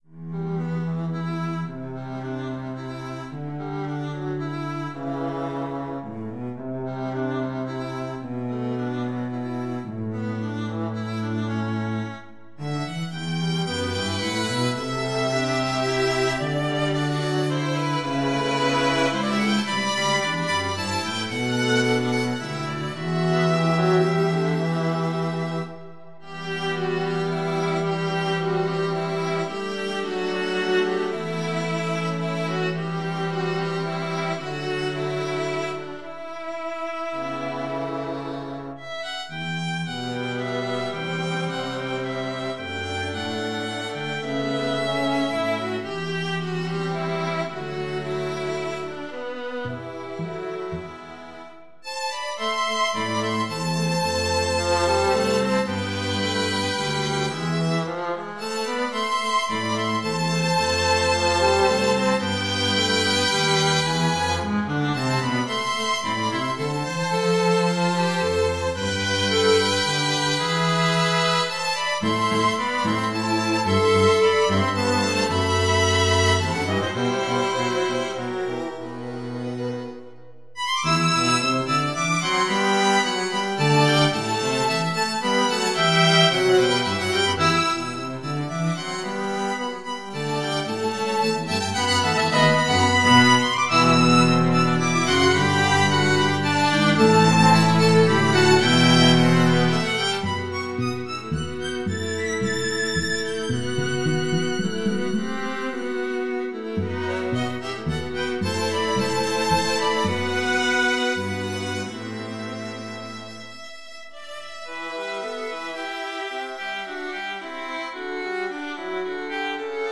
Voicing: String quartet